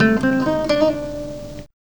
2505R GTRRIF.wav